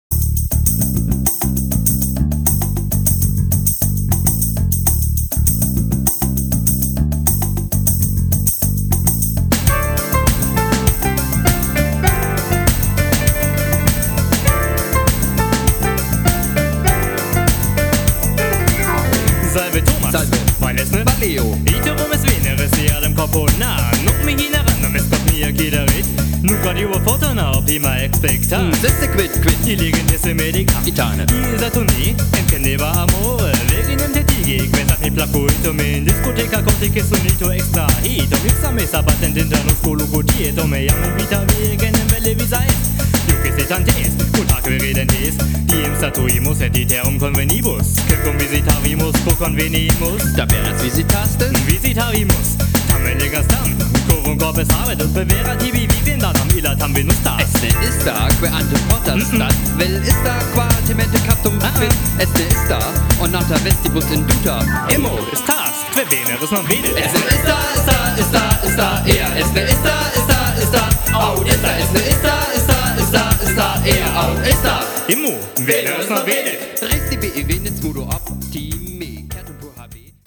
des mélodies et rythmiques soignées